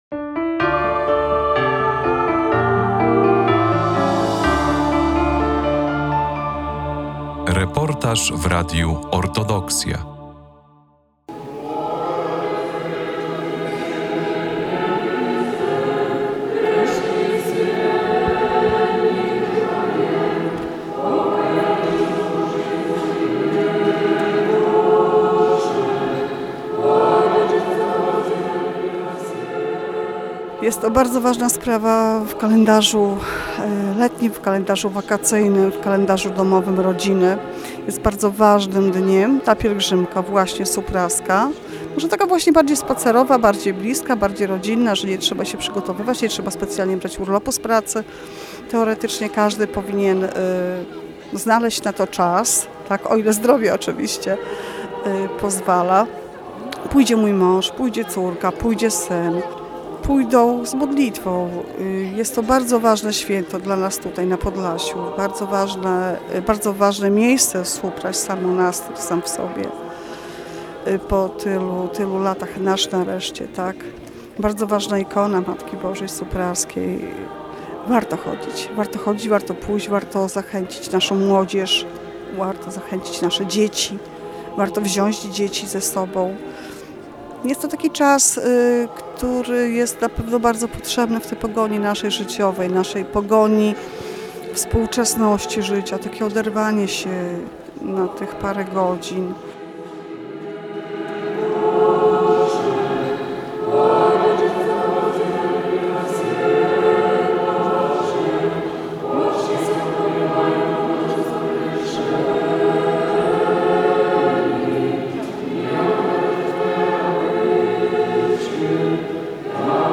W drodze do Supraśla - reportaż z pieszej pielgrzymki
9 sierpnia odbyła się piesza pielgrzymka z Białegostoku do Supraśla na święto supraskiej Ikony Matki Bożej.